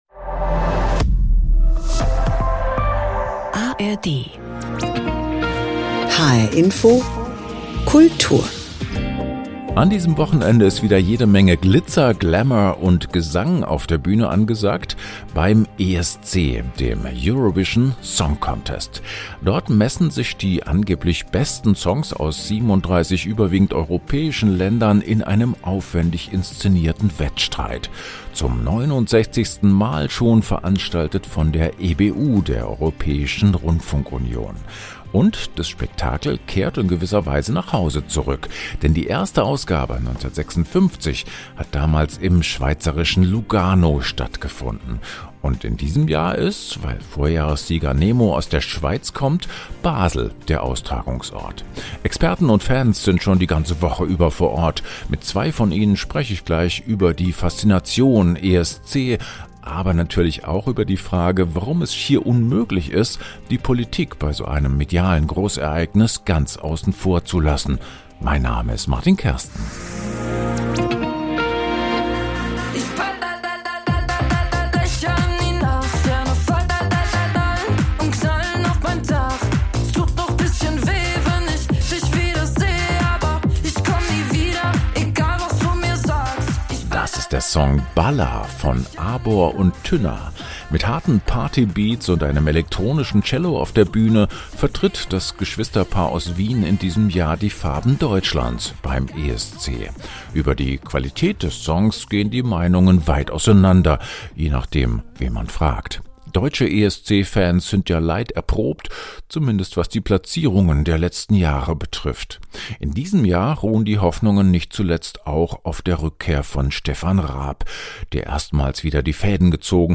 Mit ihnen sprechen wir über die Faszination ESC, aber auch über die Frage, warum es unmöglich ist, die Politik bei so einem medialen Großereignis ganz außen vor zu lassen.